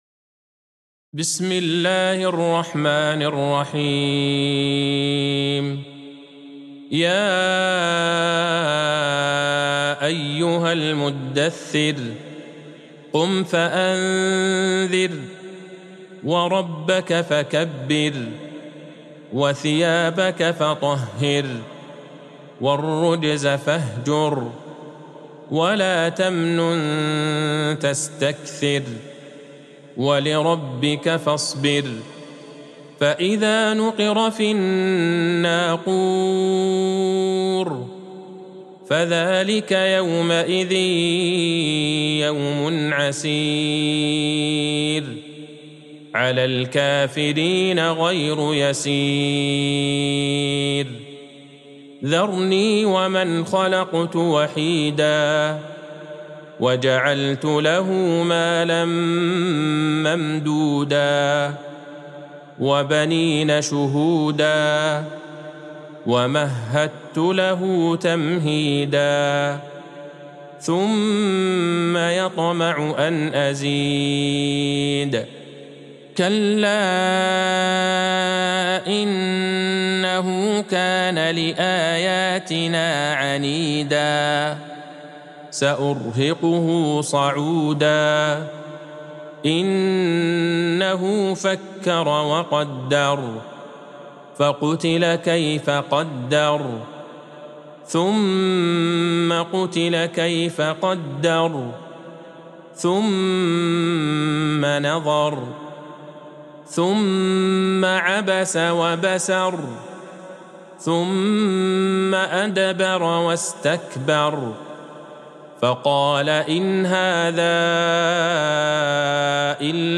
سورة المدثر Surat Al-Mudathir | مصحف المقارئ القرآنية > الختمة المرتلة ( مصحف المقارئ القرآنية) للشيخ عبدالله البعيجان > المصحف - تلاوات الحرمين